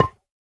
Minecraft Version Minecraft Version snapshot Latest Release | Latest Snapshot snapshot / assets / minecraft / sounds / block / bone_block / break1.ogg Compare With Compare With Latest Release | Latest Snapshot
break1.ogg